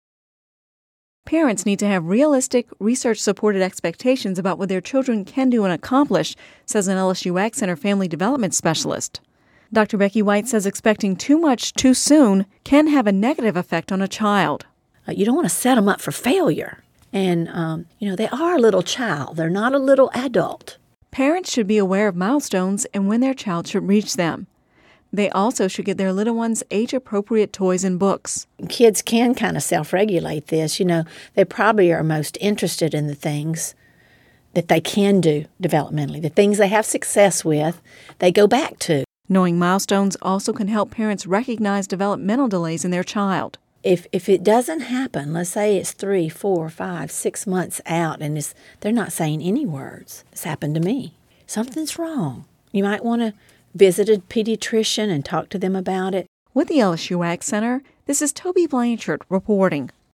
(Radio News 05/31/10) Parents need to have realistic, research-supported expectations about what their children can do and accomplish says an LSU AgCenter family development specialist.